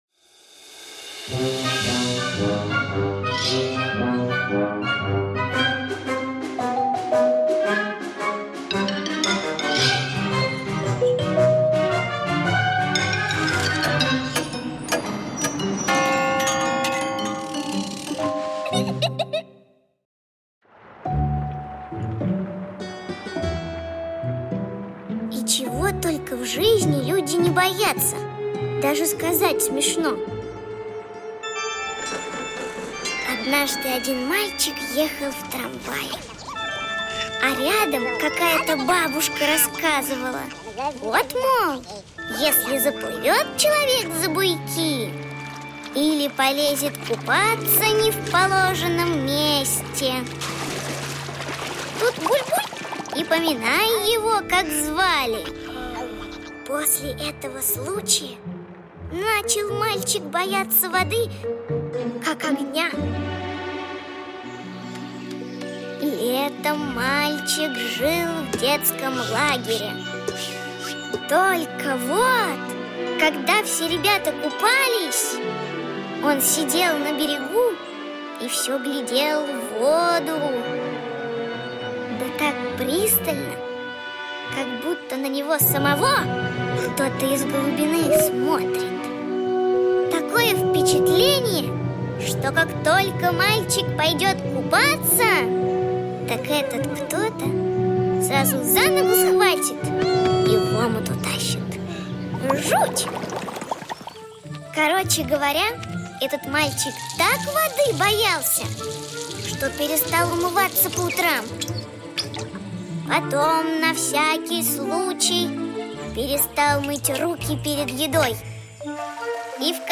Жанр: "Acapella"